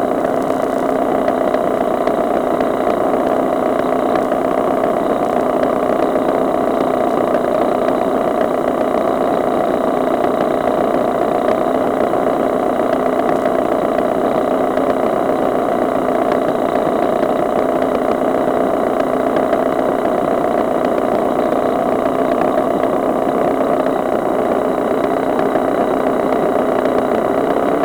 Jeg har samlet nogle lydprøver på hvordan det lyder i stedoskopet når man lytter til en Fiesta motor 1,0 MKI som er i orden.
Strømfordelerdæksel: Støj fra gnisten der springer.
stromfordelerdaeksellyd.wav